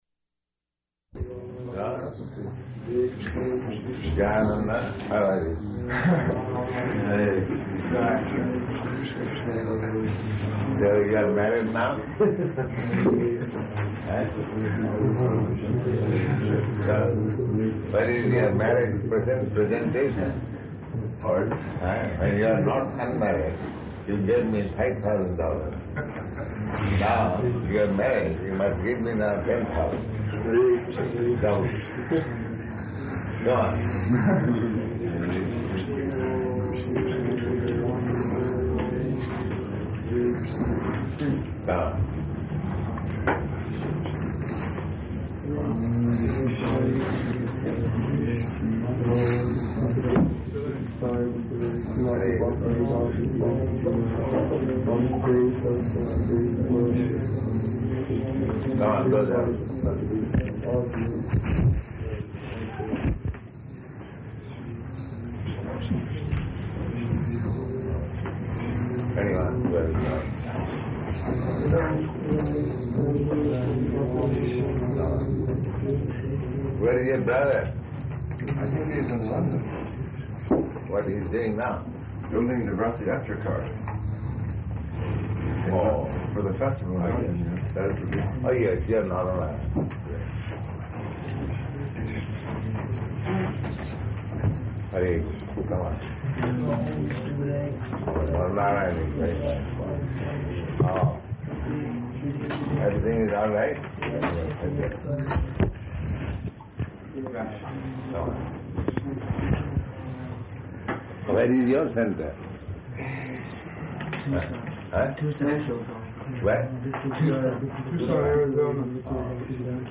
Disciple Darśana --:-- --:-- Type: Conversation Dated: June 28th 1971 Location: San Francisco Audio file: 710628DA-SAN_FRANCISCO.mp3 [sounds of devotees entering, offering obeisances, chanting japa ] Prabhupāda: ...girls?